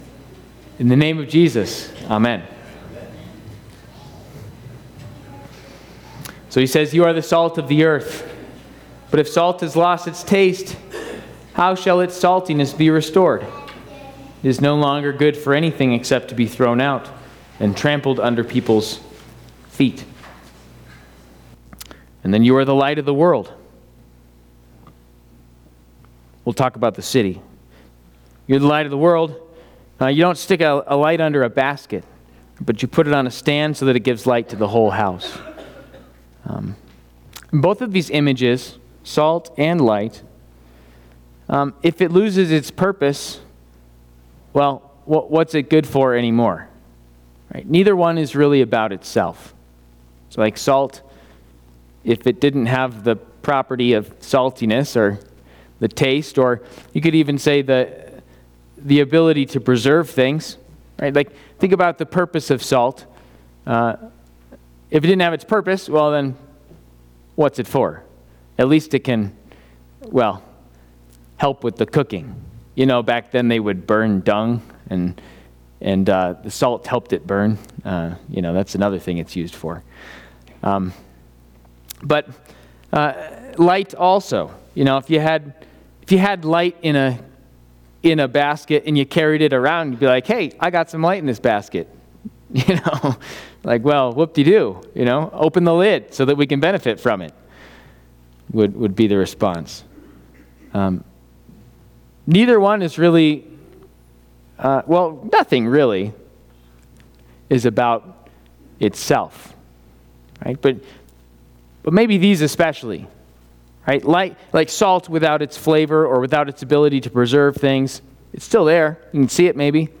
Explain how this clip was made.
Fifth Sunday after Epiphany&nbsp